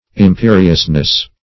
imperiousness - definition of imperiousness - synonyms, pronunciation, spelling from Free Dictionary
Imperiousness \Im*pe"ri*ous*ness\, n.